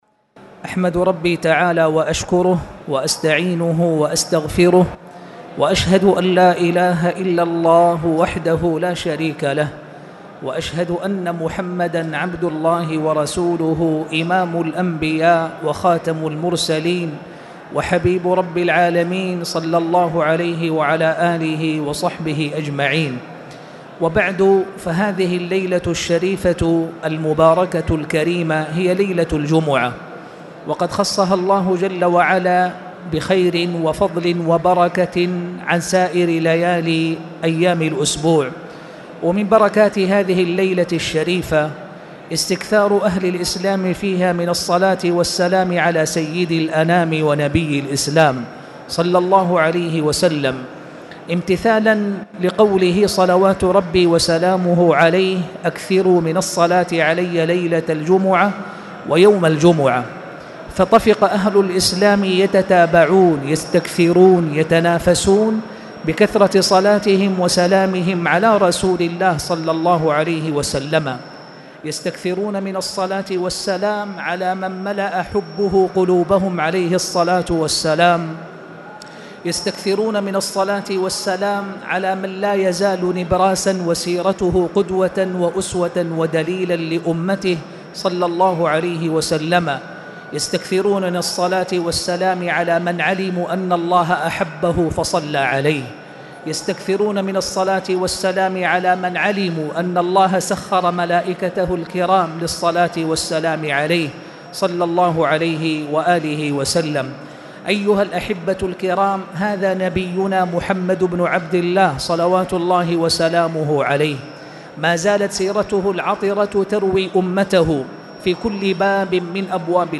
تاريخ النشر ٢٩ شعبان ١٤٣٨ هـ المكان: المسجد الحرام الشيخ